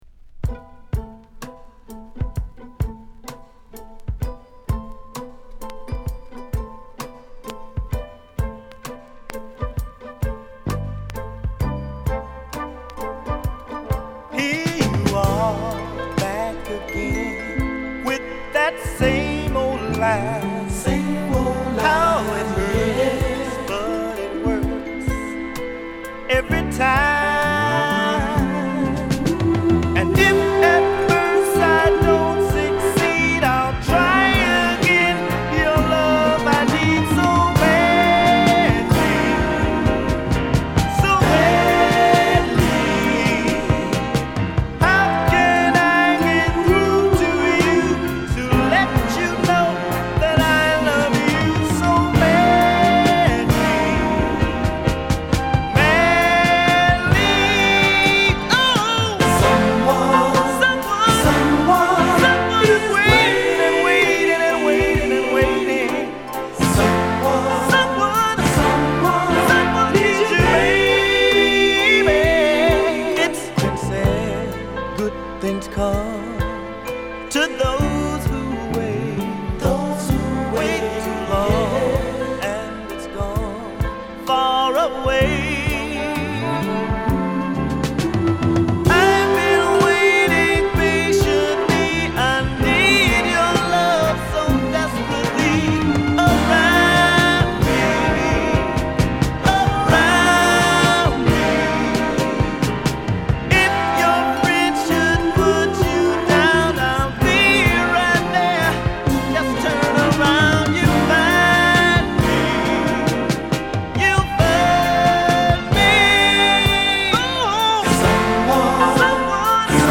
極上の西海岸メロウが詰まったグッドアルバム